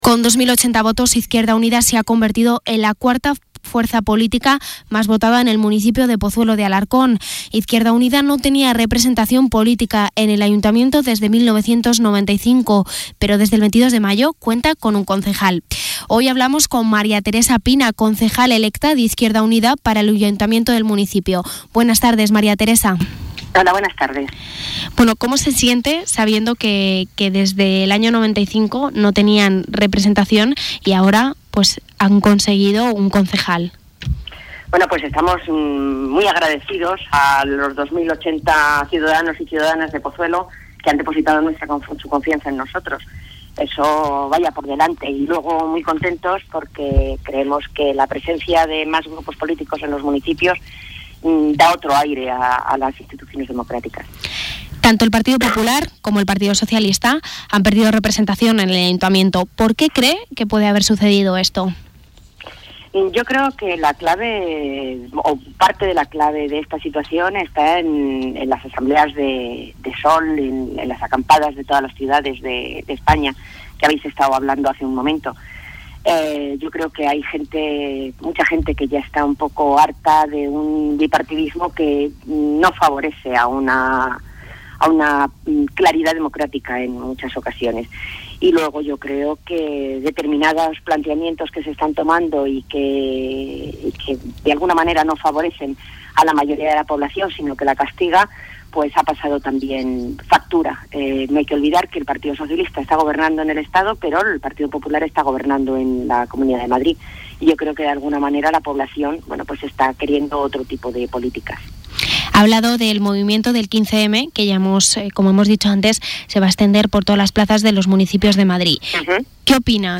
El pasado jueves, María Teresa Pina concedió una entrevista al programa de Pozuelo Radio, 'Pozuelo se Mueve', donde habló de temas como el Movimiento 15-M o la gestión del anterior gobierno en el municipio.